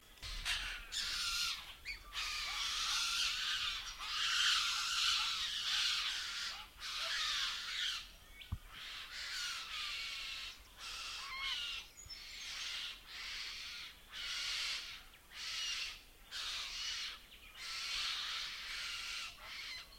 To hear calls of the choughs, click on the sound icons below:
Chicks